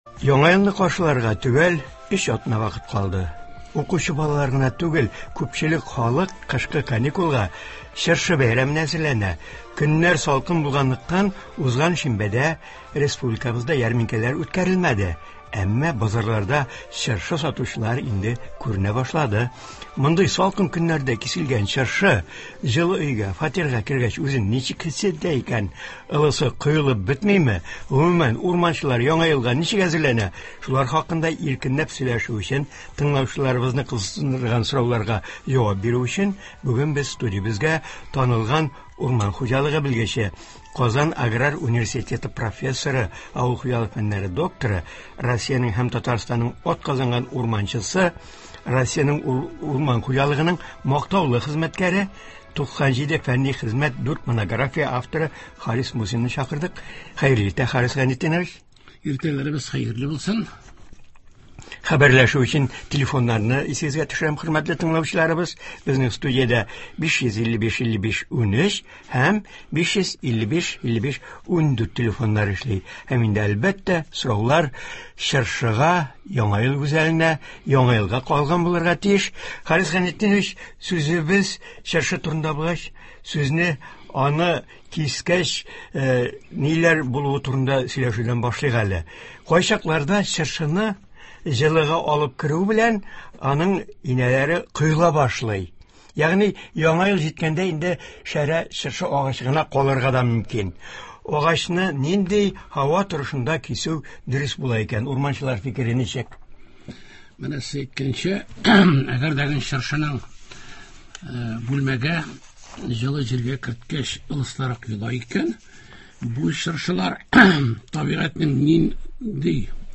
Республикабызда иске елны озату, яңа елны каршылау, чыршыларны бизәү, Яңа ел кичәләре үткәрү буенча матур традицияләр бар. Болар хакында турыдан-туры эфирда Татарстанның һәм Россиянең атказанган урманчысы
тыңлаучылар сорауларына җавап бирә